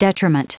Prononciation du mot detriment en anglais (fichier audio)
Prononciation du mot : detriment